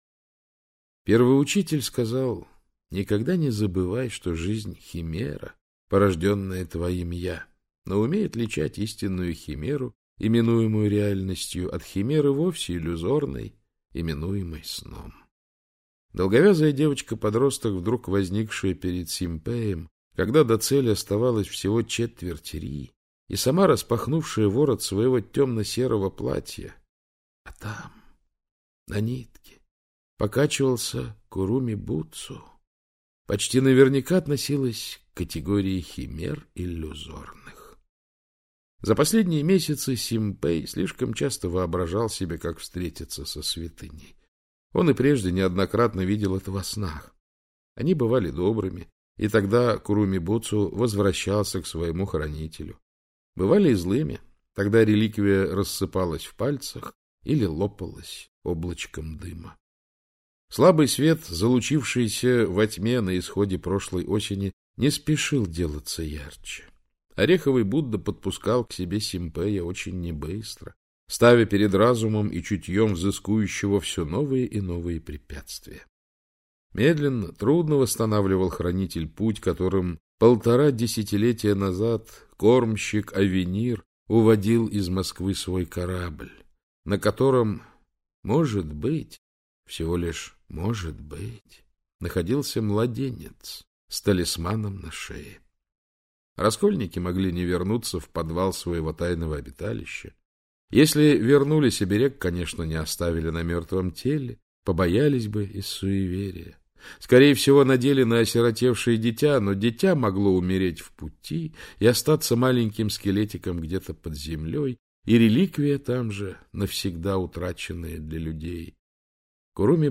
Аудиокнига Ореховый Будда - купить, скачать и слушать онлайн | КнигоПоиск